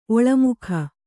♪ oḷamukha